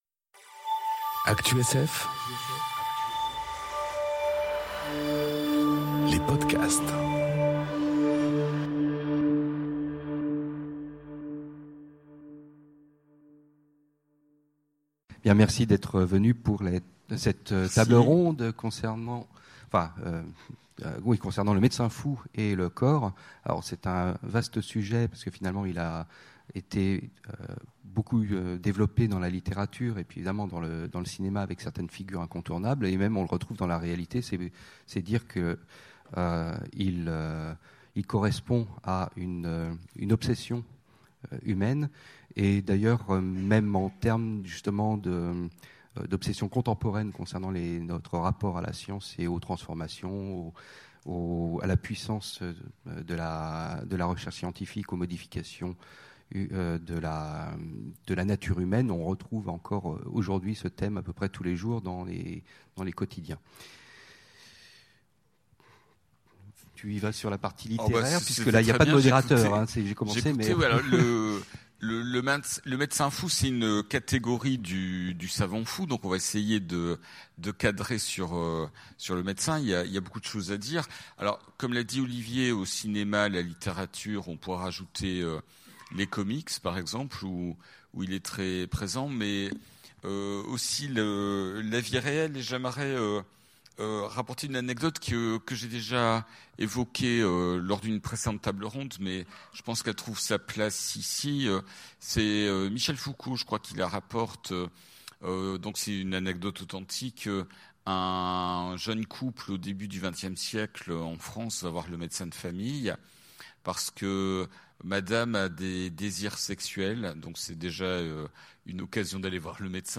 Conférence Le médecin fou et le corps enregistrée aux Utopiales 2018